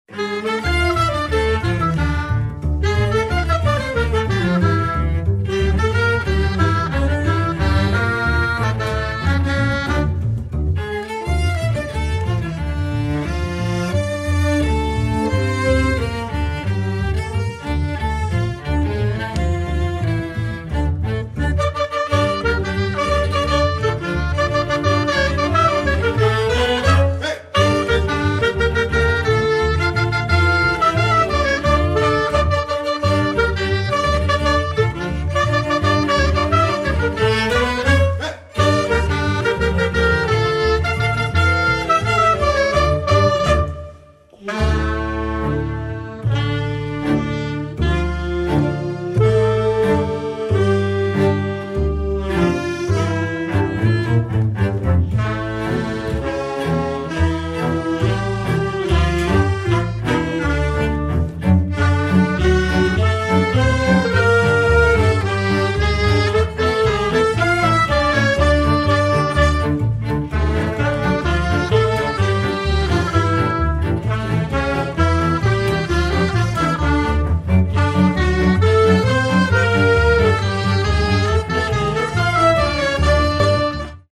(Traditional Music)